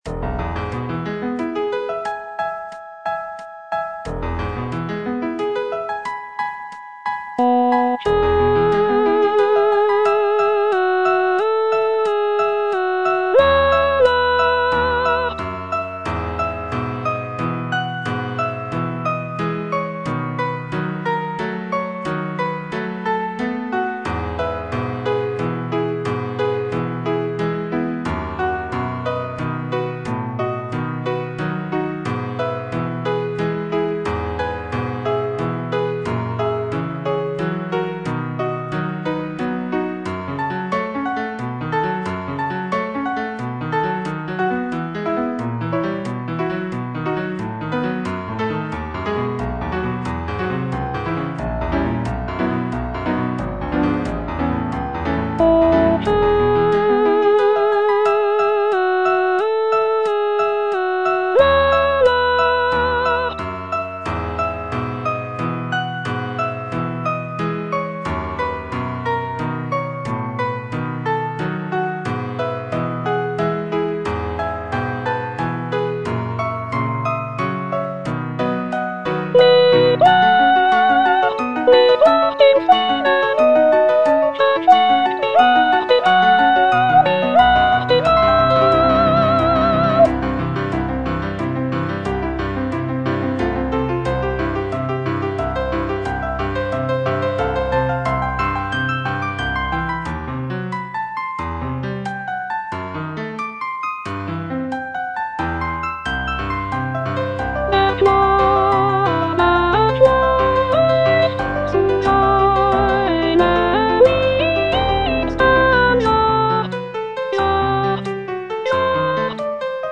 O schöne Nacht - Soprano (Voice with metronome) Ads stop: auto-stop
Completed in 1884, these quartets are set for four-part mixed choir with piano accompaniment.